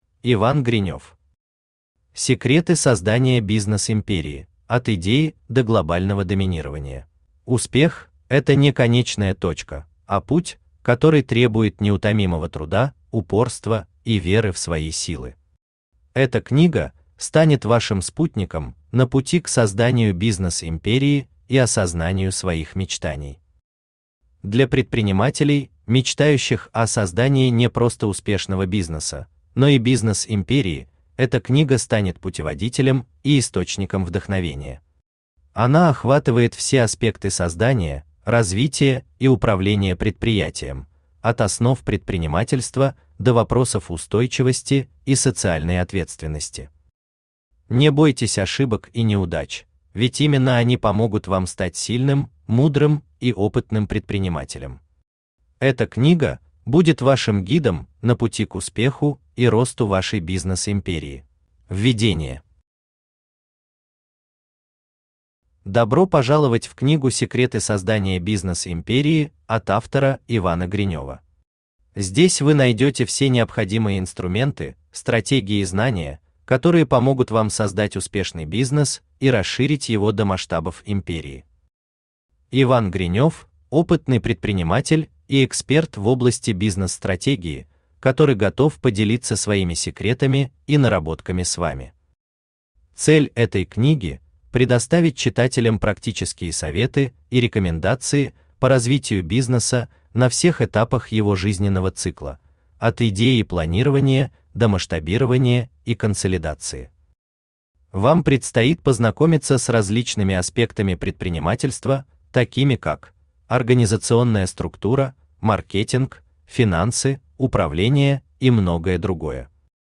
Aудиокнига Секреты создания бизнес-империи: от Идеи до Глобального Доминирования Автор Иван Викторович Гринёв Читает аудиокнигу Авточтец ЛитРес.